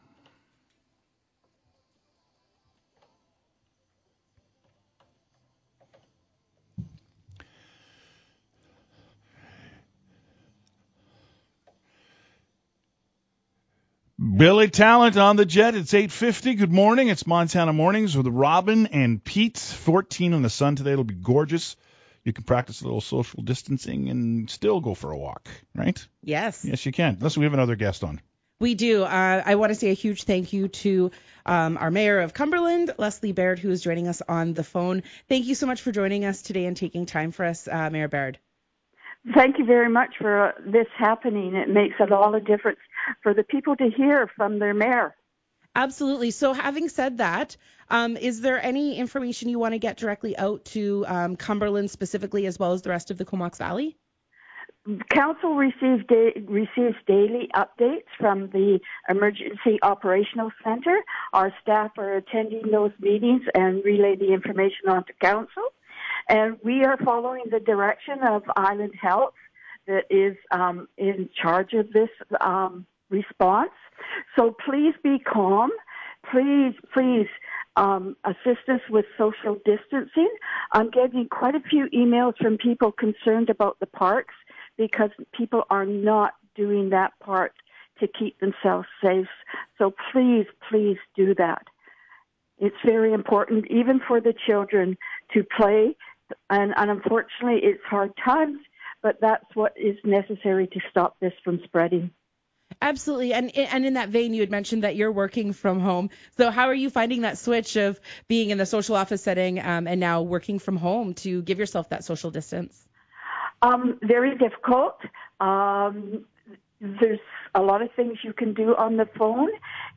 That person will be interviewed live during Montana Mornings